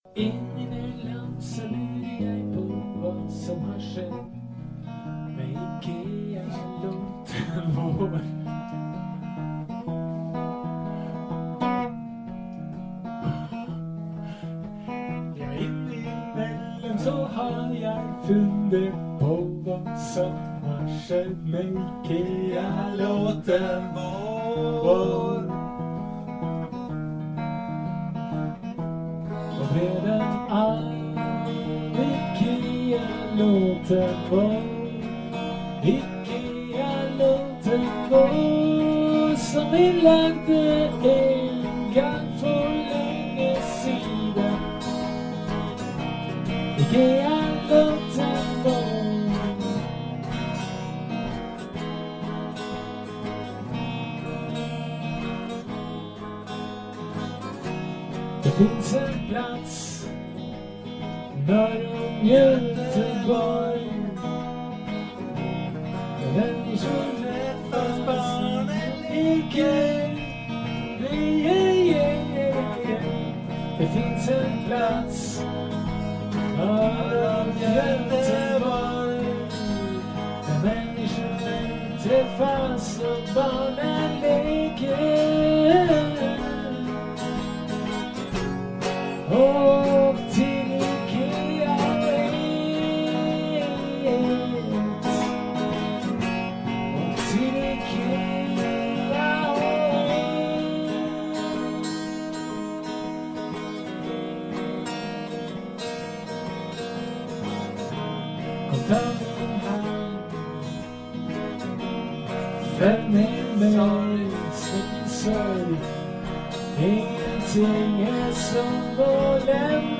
Ganska tidigt in på det nya året hade vi en tre timmars lång jam, där vi var väldigt inspirerade, och skapade mycket nytt.